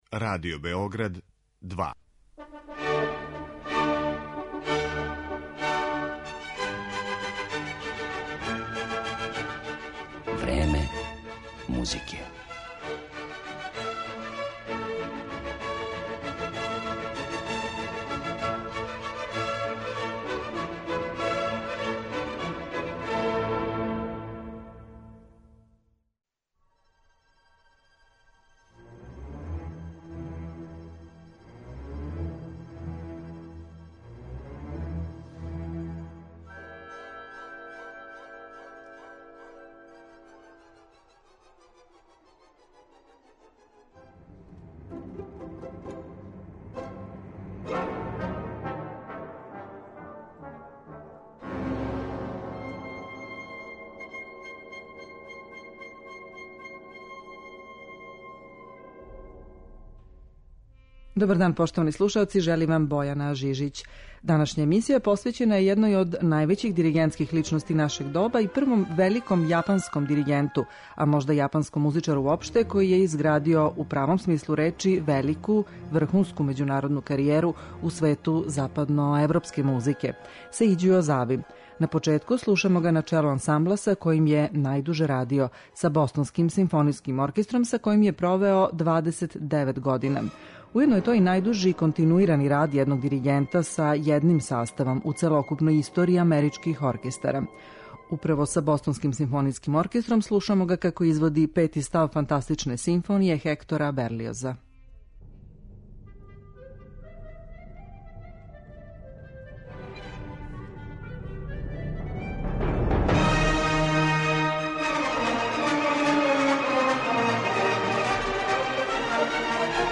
Слушаћемо га са ансамблом на чијем је челу био готово три деценије, Бостонским симфонијским оркестром, али и са Саито Кинен оркестром, Чикашким симфонијским оркестром, Берлинском и Бечком филхармонијом.